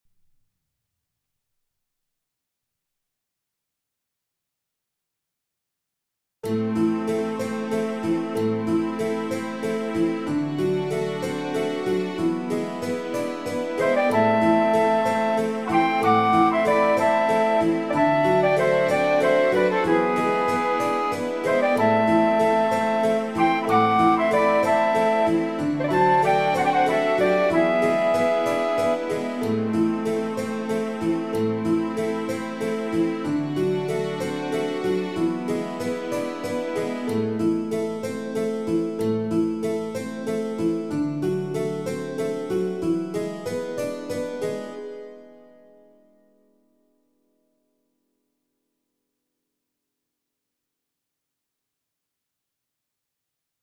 Atop that E major however, the flutes will avoid playing any G notes and instead will focus on G# to help match the underlying E chord.
Breaking the Dorian tonality with a dominant chord
I love that sound - it begins with wistful, nostalgic, adventurous flair, which is characteristic of Dorian. Then with the arrival of the E major chord, we're also delivered some fiery tension.
dorian-dominant.mp3